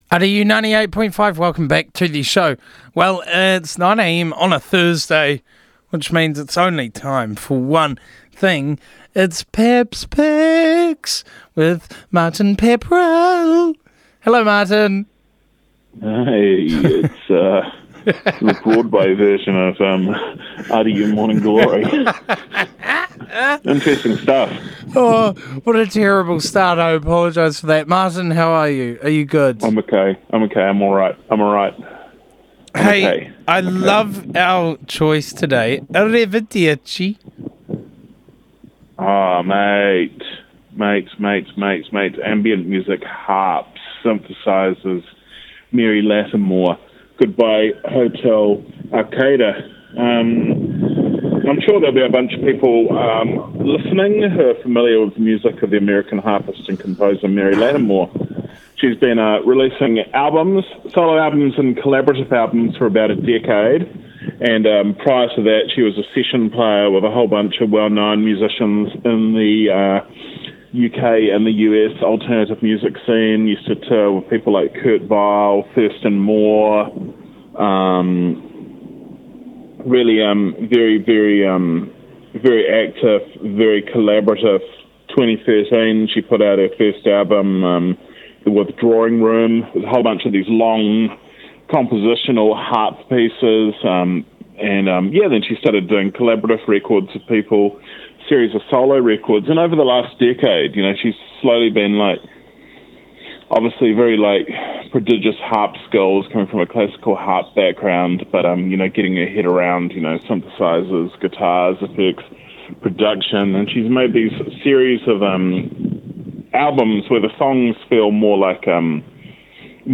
A beautiful, ethereal piece, it’s something you’re not likely to hear on RDU much, but it’s still remarkable in its own way.